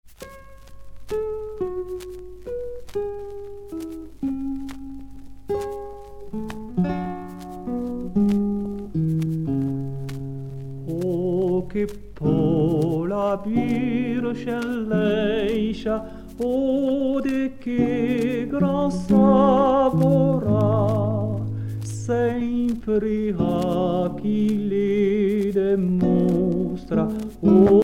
prière, cantique
Pièce musicale éditée